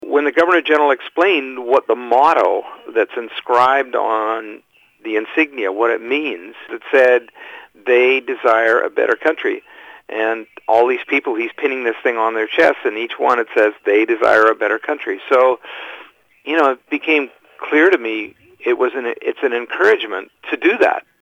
He explains what being part of the Order of Canada means.